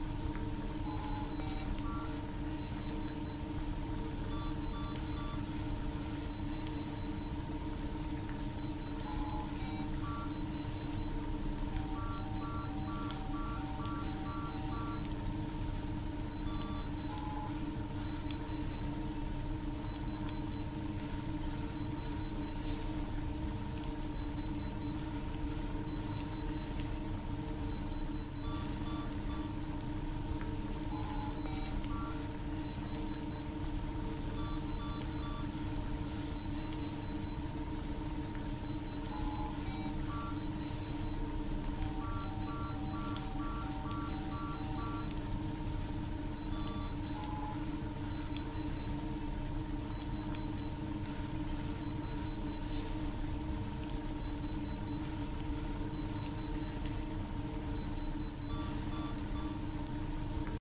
main-ambient.ogg